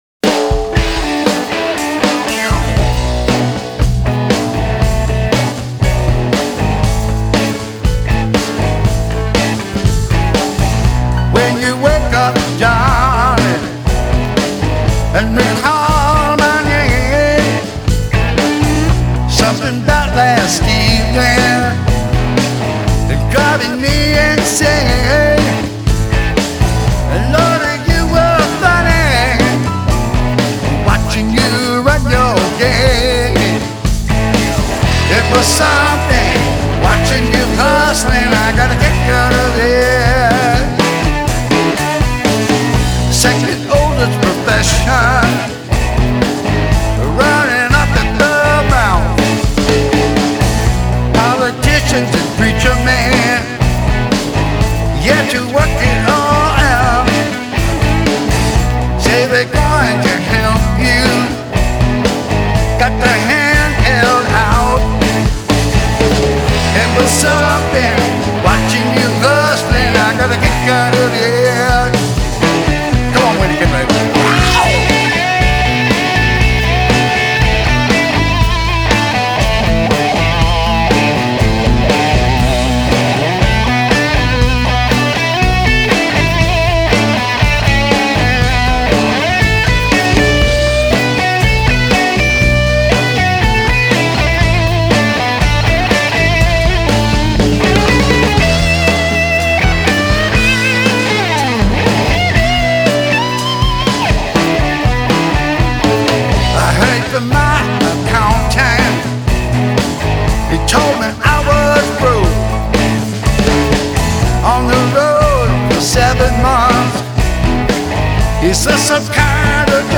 Genre: Blues, Blues Rock